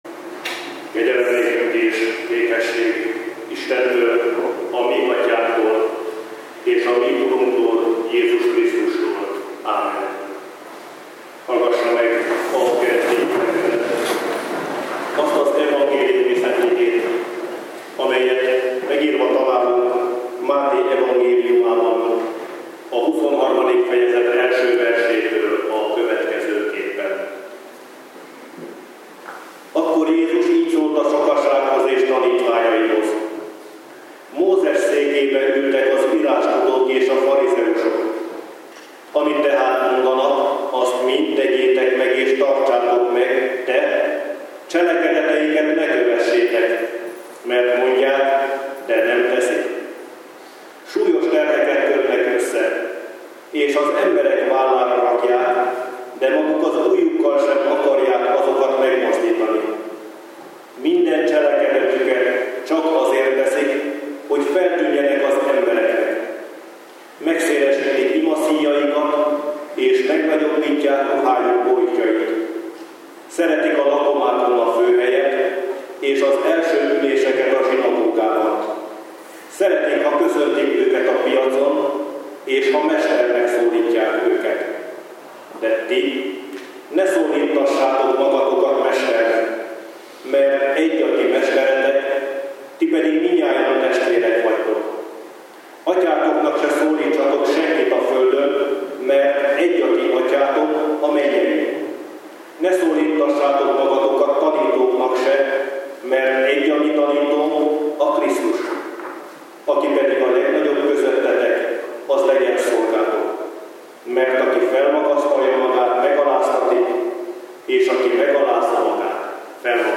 Szentháromság ünnepe után 16. vasárnap